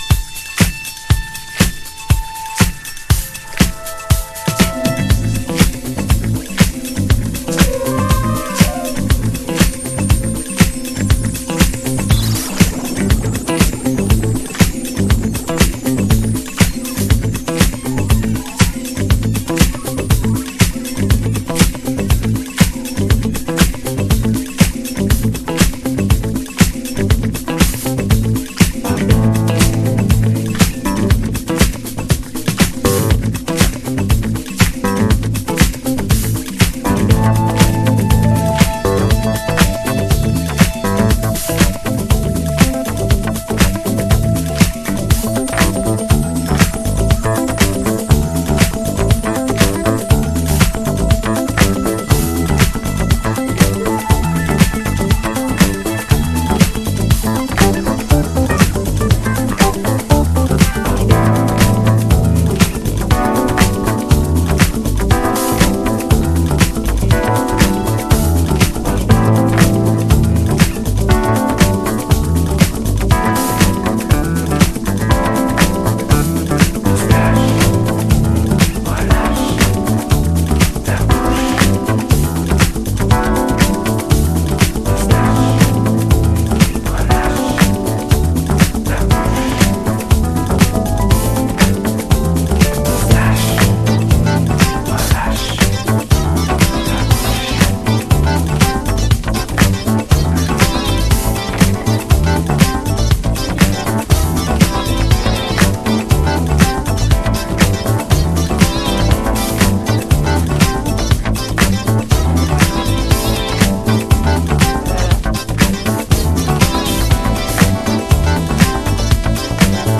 Alt Disco / Boogie